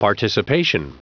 Prononciation du mot participation en anglais (fichier audio)
Prononciation du mot : participation